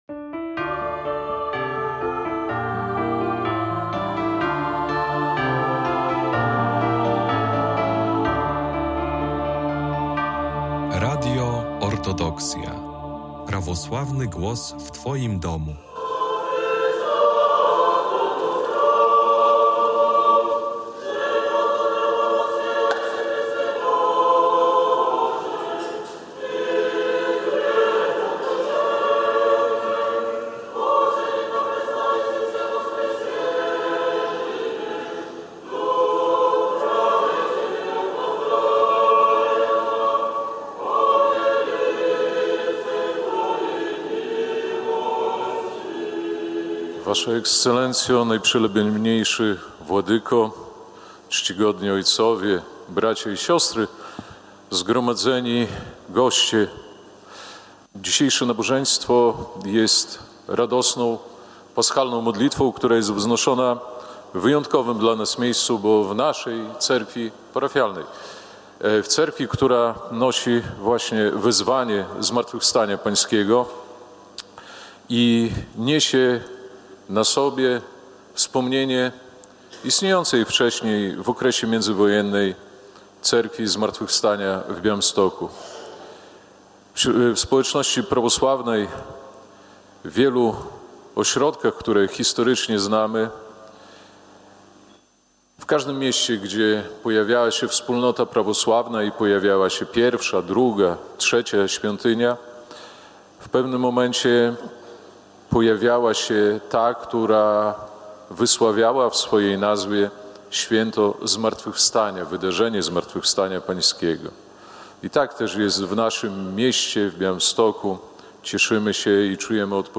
19 kwietnia 2026 roku w Parafii Prawosławnej Zmartwychwstania Pańskiego w Białymstoku odbywały się uroczystości święta parafialnego Niedzieli Antypaschy. Uroczystej Boskiej Liturgii przewodniczył Jego Ekscelencja Najprzewielebniejszy Jakub Arcybiskup Białostocki i Gdański.
Zapraszamy do wysłuchani relacji z tego wydarzenia.